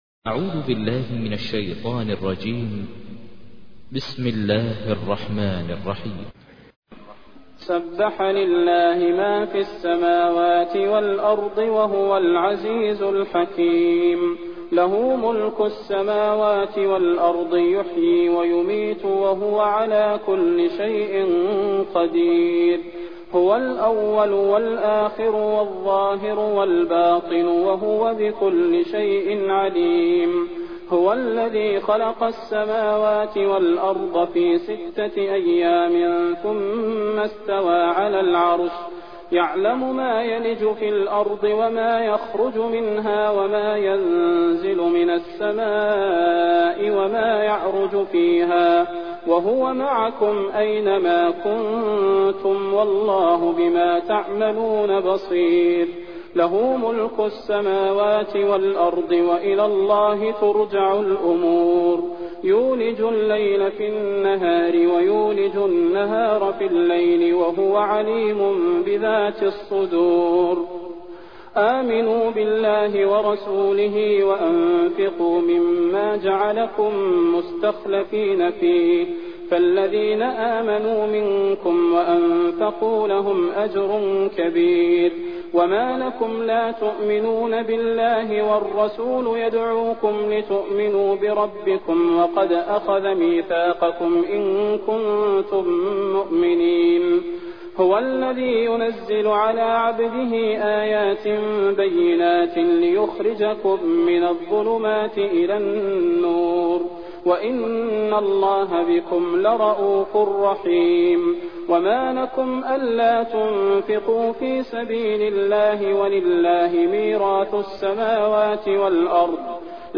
تحميل : 57. سورة الحديد / القارئ ماهر المعيقلي / القرآن الكريم / موقع يا حسين